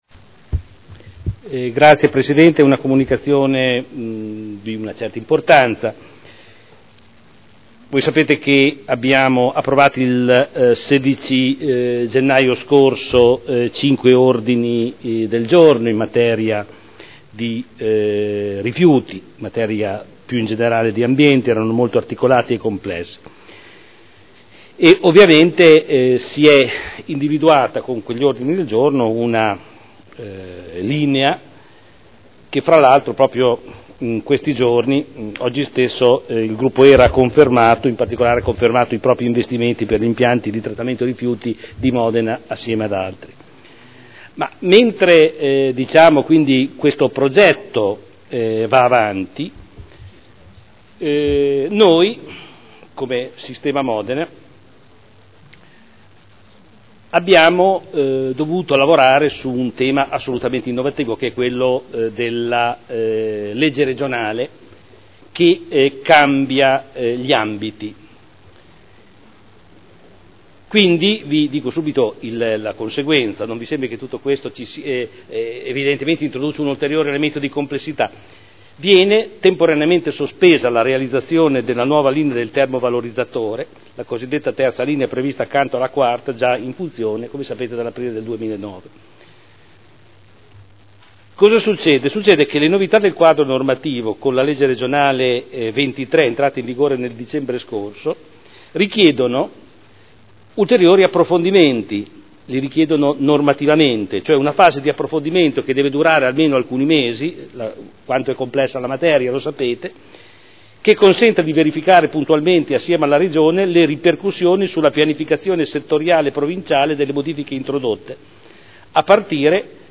Giorgio Pighi — Sito Audio Consiglio Comunale
Comunicazioni del sindaco su termovalorizzatore